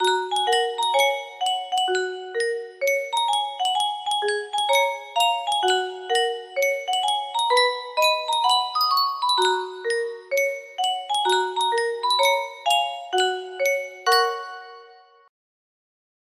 Yunsheng Boite a Musique - Joli tambour 6195 music box melody
Full range 60